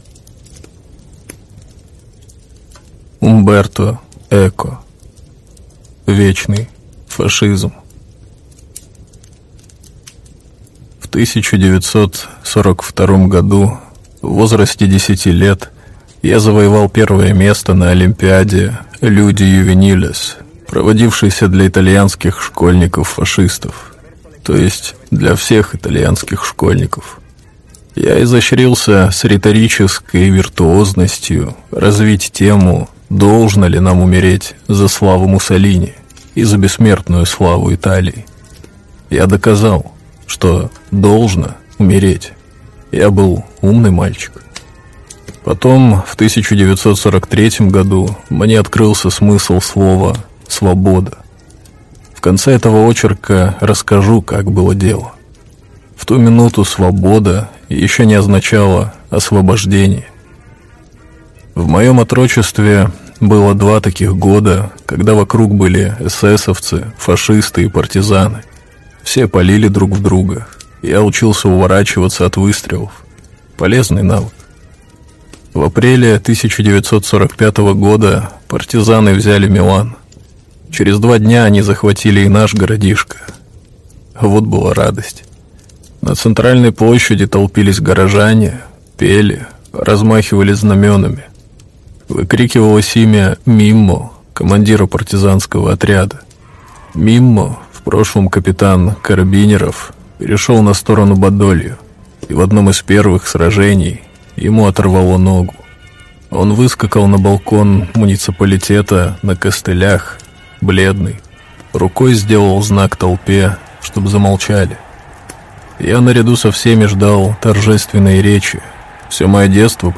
Скачать аудио книгу Вечный фашизм Умберто, Скачать аудио книгу бесплатно Вечный фашизм Умберто